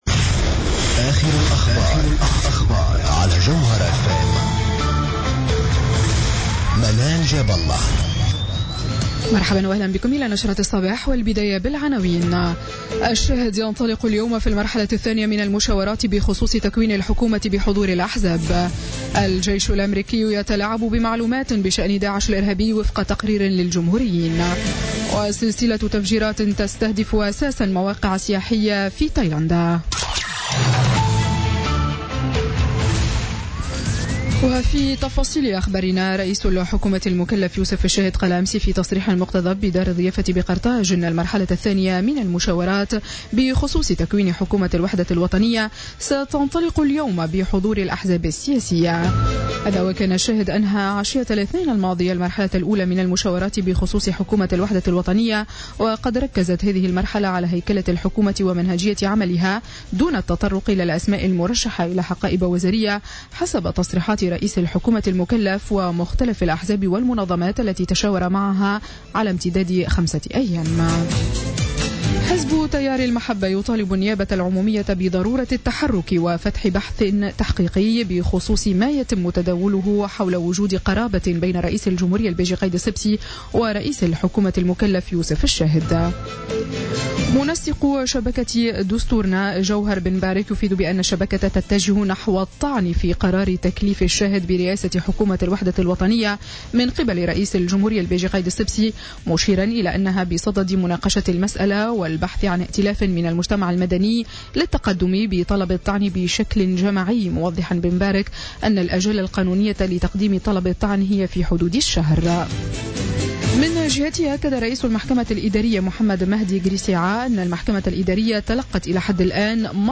نشرة أخبار السابعة صباحا ليوم الجمعة 12 أوت 2016